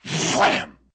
Sound effect used for when a Kasplat attacks in Donkey Kong 64.